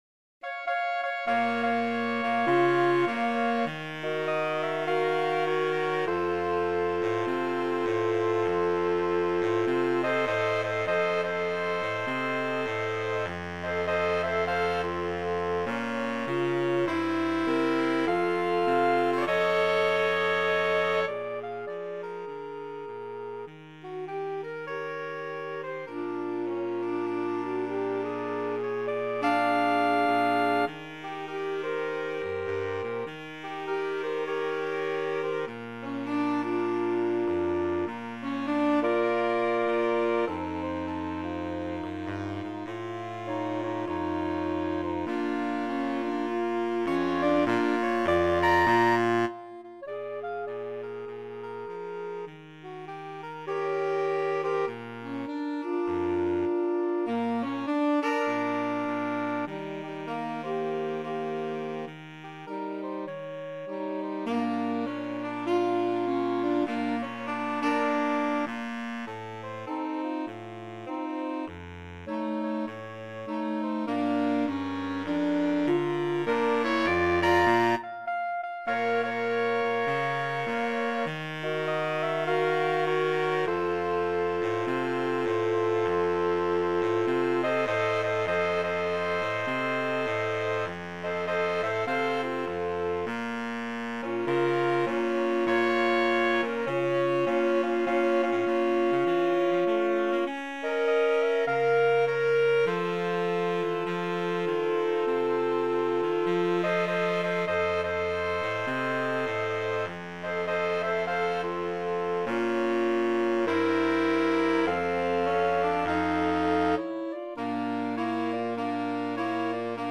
4/4 (View more 4/4 Music)
With a swing =c.100
Jazz (View more Jazz Saxophone Quartet Music)